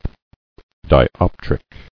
[di·op·tric]